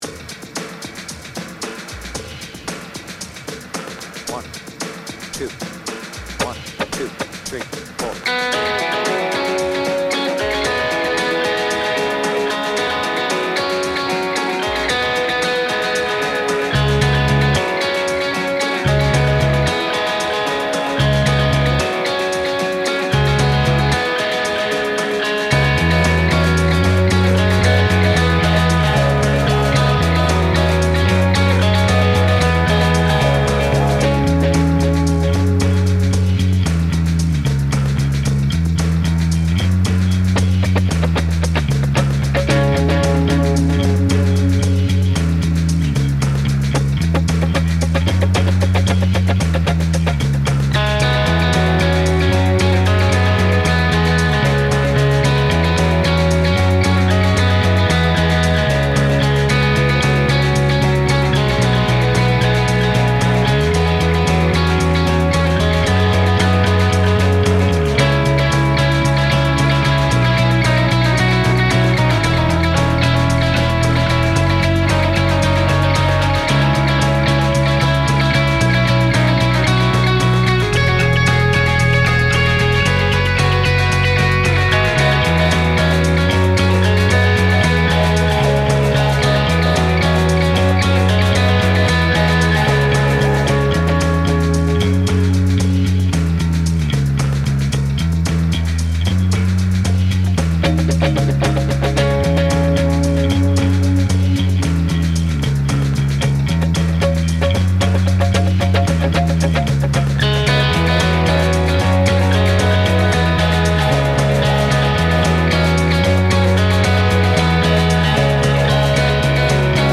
BPM : 113
Tuning : Eb
Without vocals